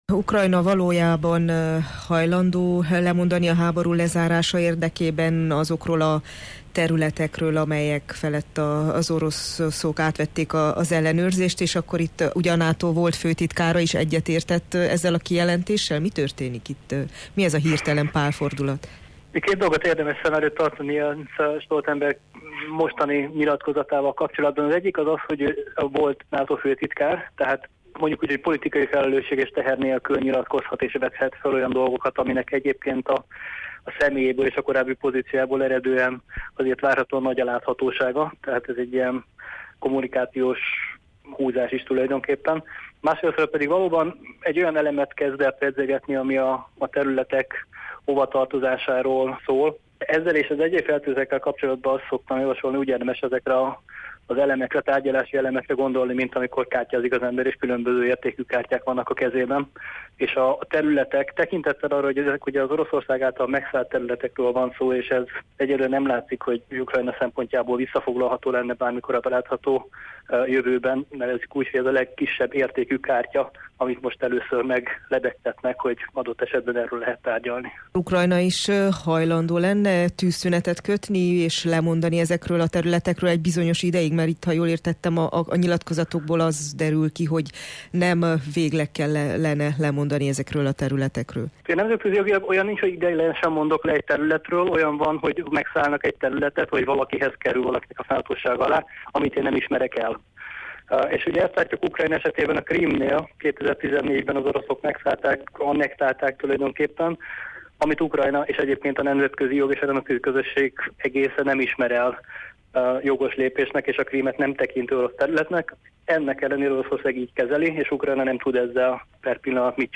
szakértőt kérdezte